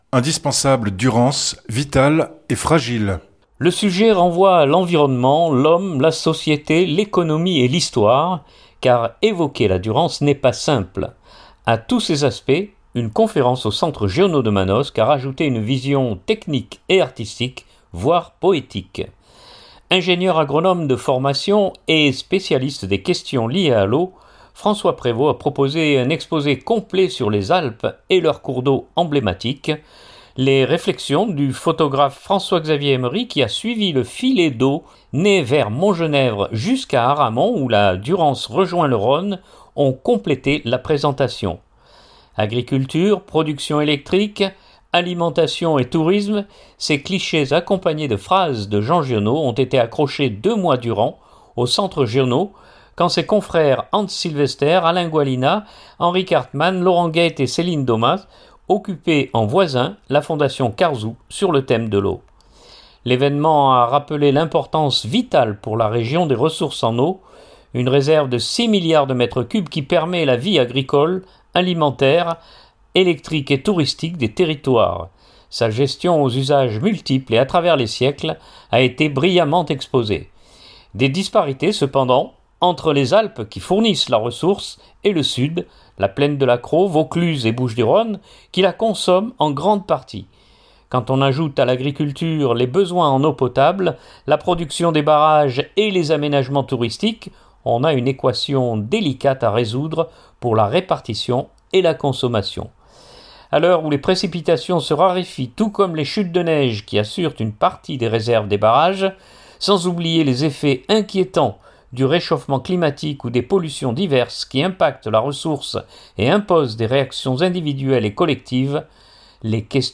A tous ces aspects, une conférence au Centre Giono de Manosque a rajouté une vision technique et artistique voire poétique.